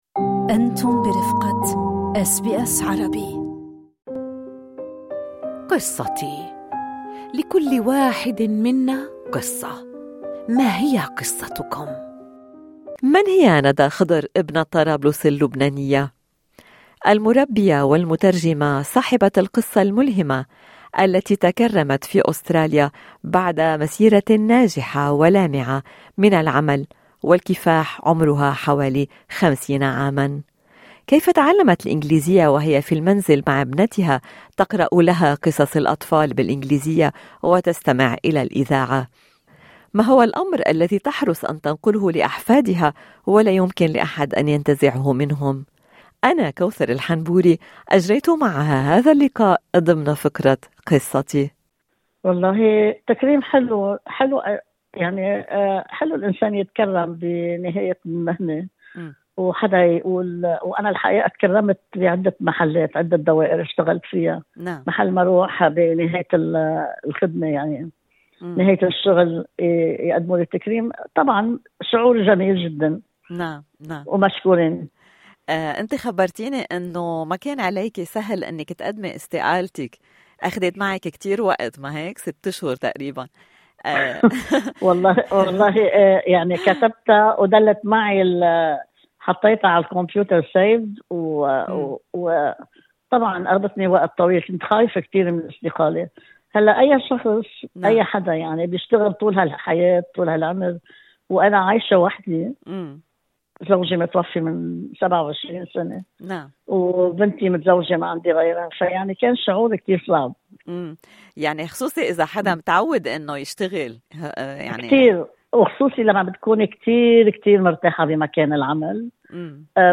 وبعد أن أصبحت جدة ما الأمر الذي تحرص على أيصاله لأحفادها ولا يمكن لأحد أن يأخذه منهم: الإجابة ضمن المقابلة في الملف الصوتي أعلاه.